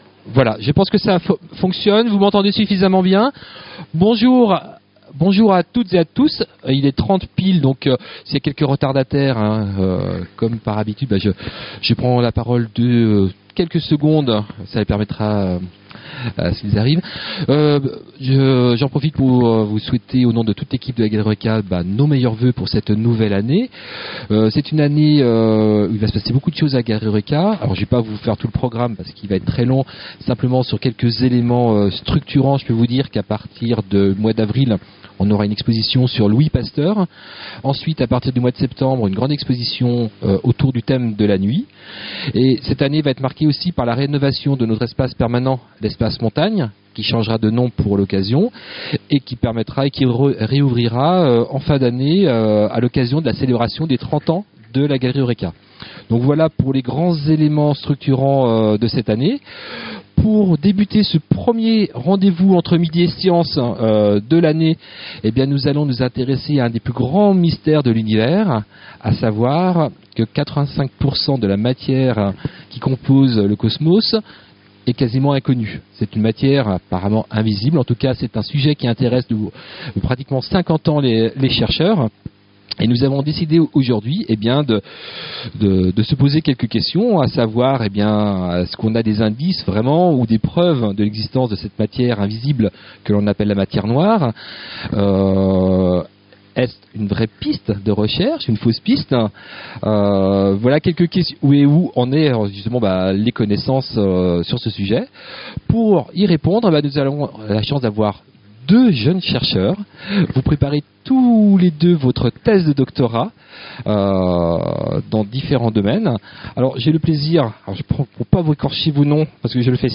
Une fois par mois, à la Galerie Eurêka, venez rencontrer des spécialistes, poser vos questions et débattre avec eux lors des rendez-vous « Entre midi & science ».